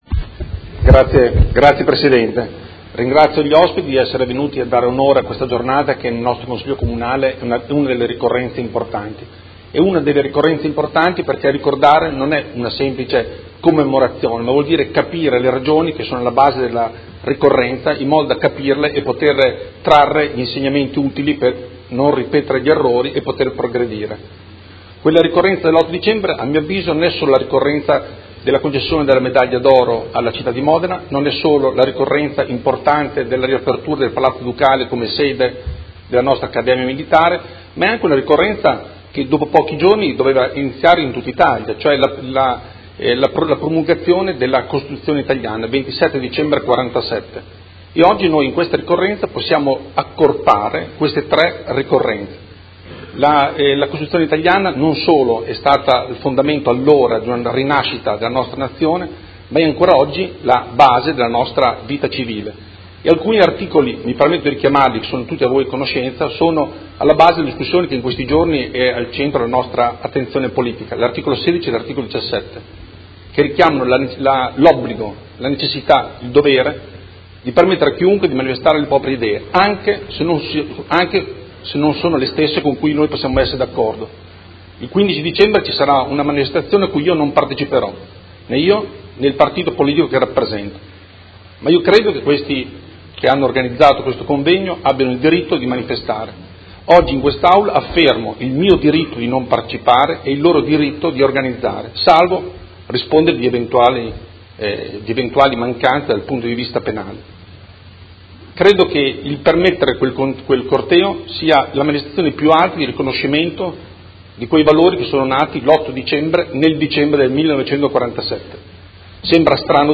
Seduta del 08/12/2017 Modena Medaglia d'oro al Valor Militare. 70° anniversario.